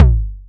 edm-perc-06.wav